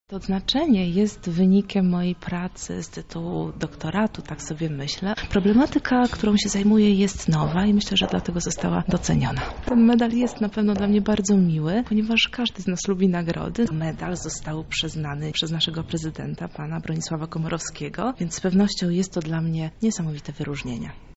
Na Uniwersytecie Marii Curie Skłodowskiej odbyła się uroczystość wręczenia krzyży zasługi za długoletnią prace i wybitne osiągnięcia.